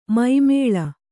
♪ mai mēḷa